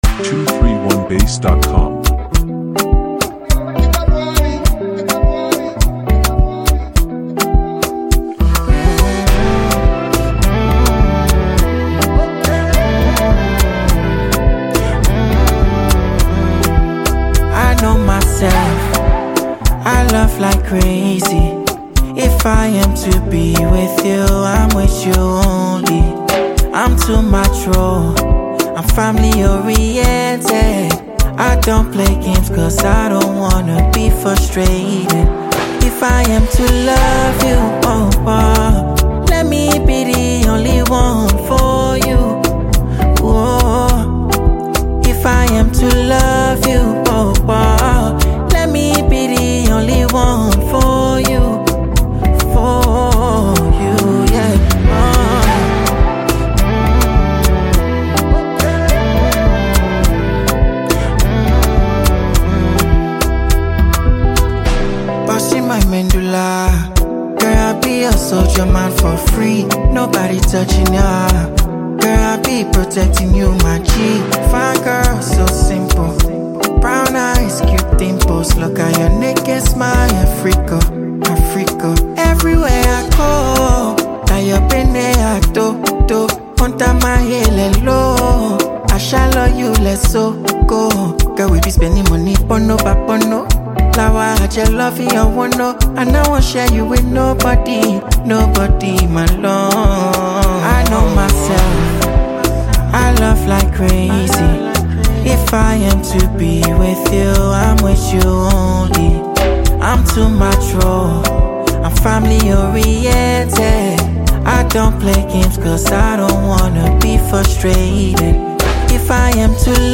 is back with a romantic anthem!
The energy’s intimate, the lyrics are sweet
smooth delivery